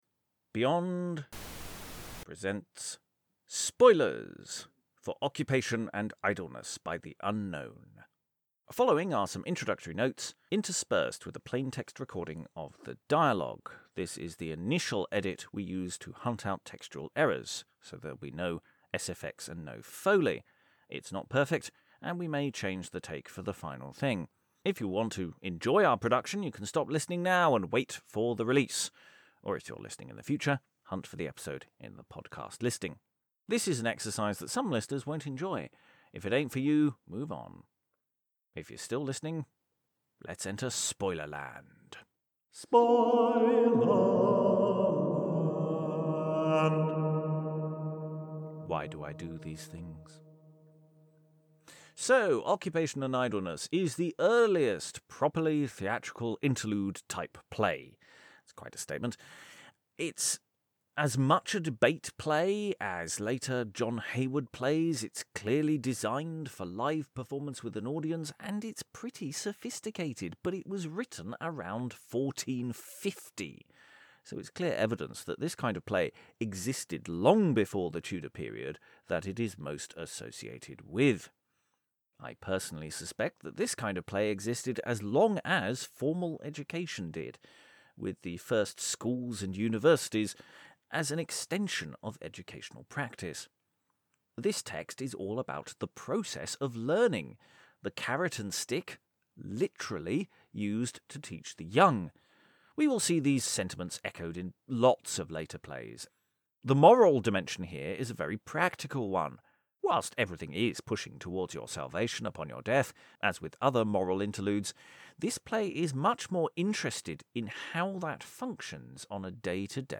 It's the !Spoilers! episode for our full cast audio adaptation of Occupation and Idleness by the Unknown - based on the live stream session on YouTube, and mixed for your delectation and pleasure.